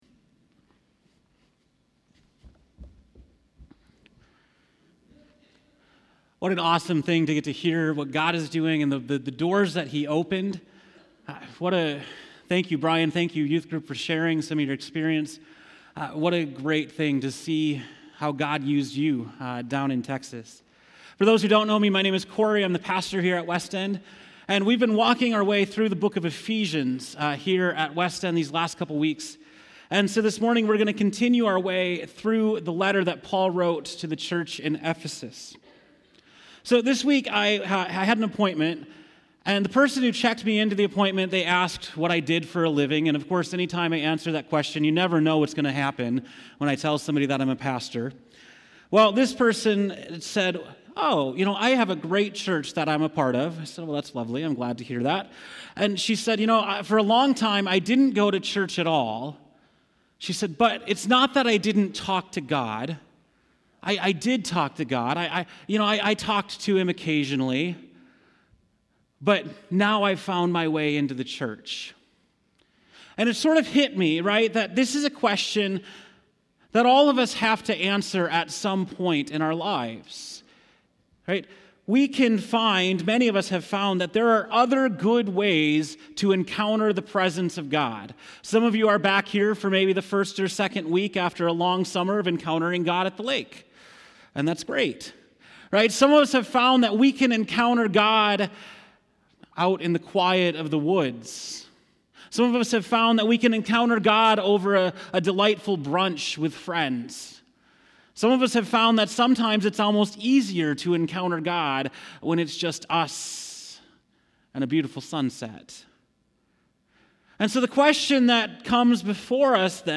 September 23, 2018 (Morning Worship)